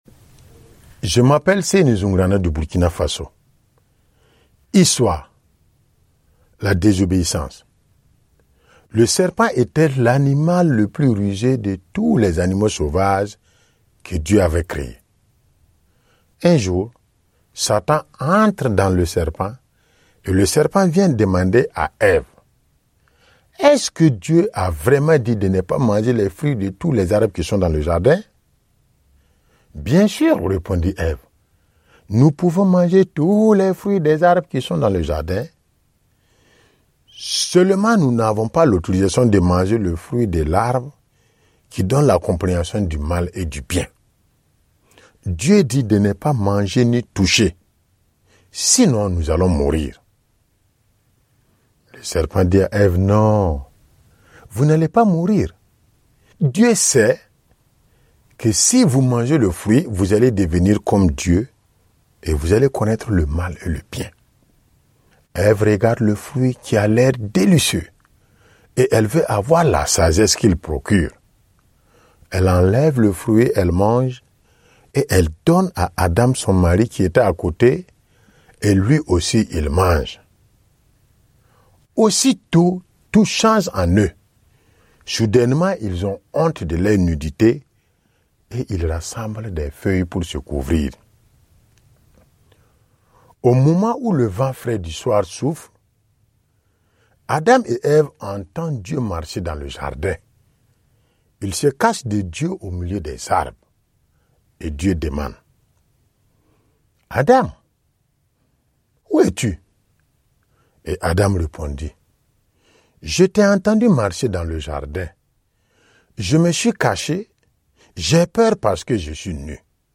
raconter l'histoire de la manipulation originelle.